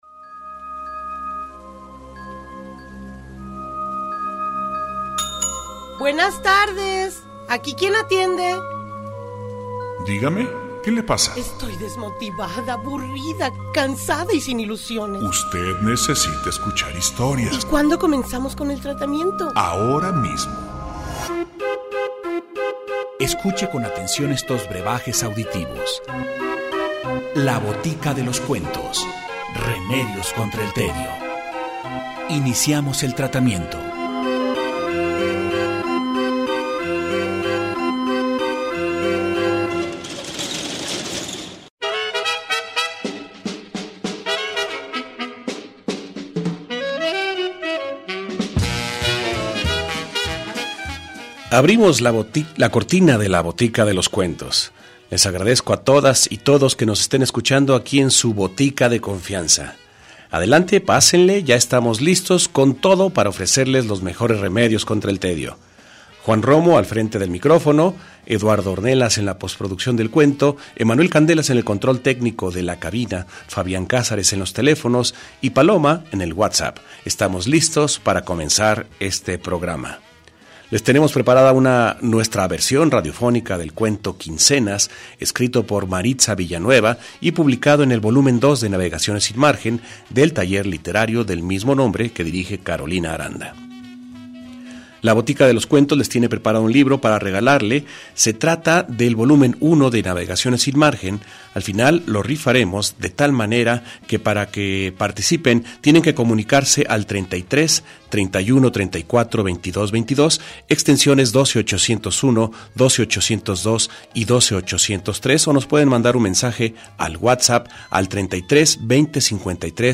El día de hoy escuchamos el cuento "Quincenas" escrito por Maritza Villanueva Sintoniza el 104.3FM